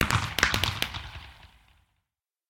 twinkle_far1.ogg